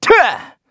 Kibera-Vox_Attack2_kr.wav